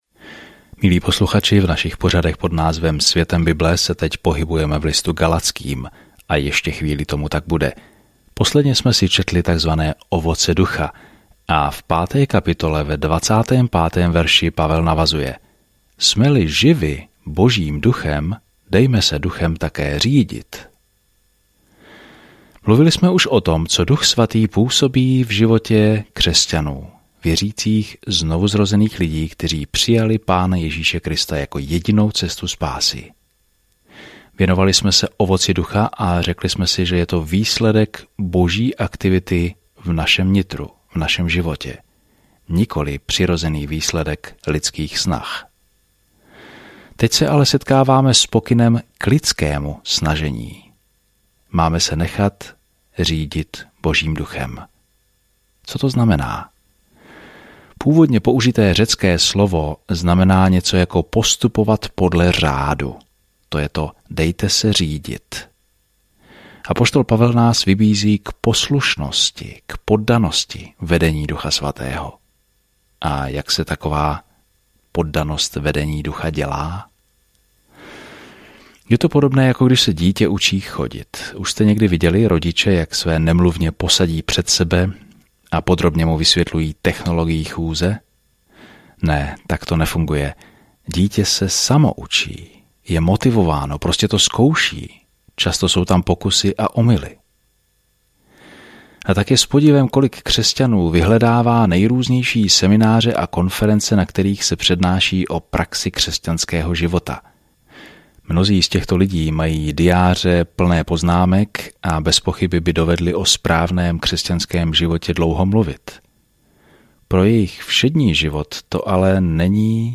Písmo Galatským 5:25-26 Galatským 6:1-2 Den 14 Začít tento plán Den 16 O tomto plánu „Pouze vírou“ jsme spaseni, ne ničím, čím bychom si zasloužili dar spasení – to je jasné a přímé poselství listu Galaťanům. Denně procházejte Galatským, poslouchejte audiostudii a čtěte vybrané verše z Božího slova.